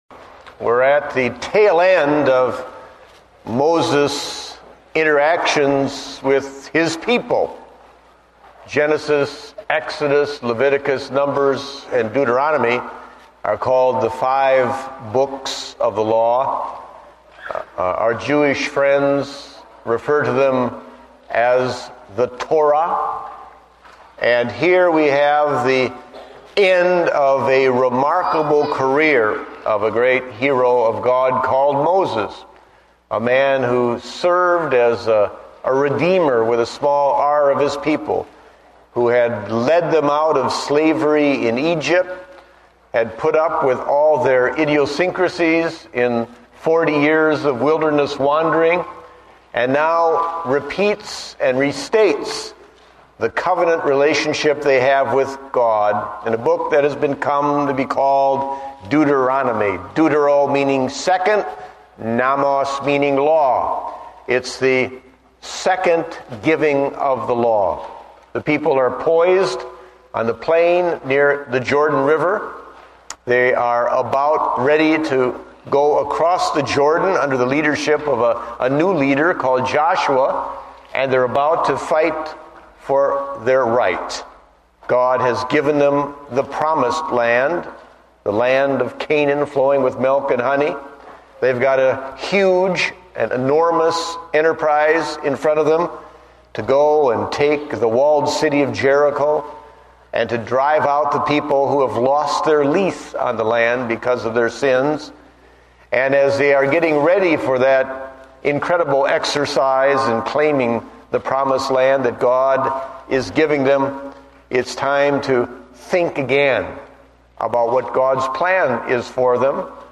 Date: March 22, 2009 (Morning Service)